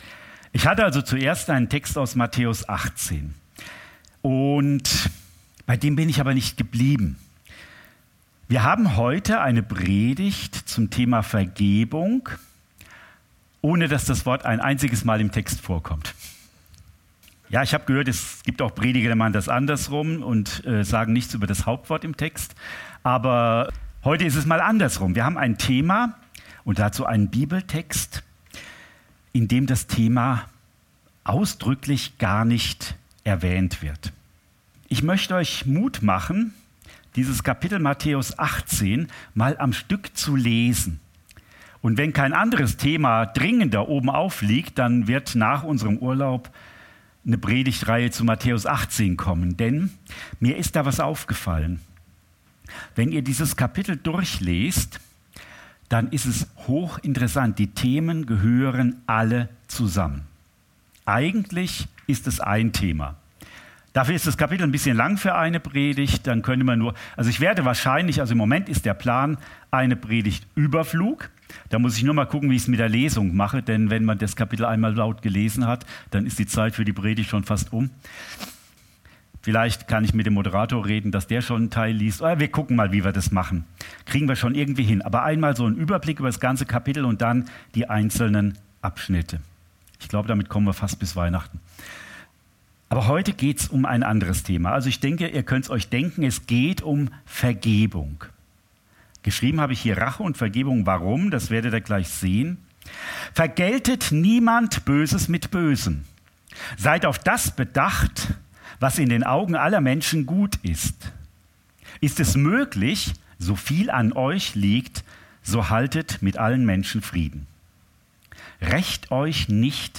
Predigt vom 01. September 2024 – Süddeutsche Gemeinschaft Künzelsau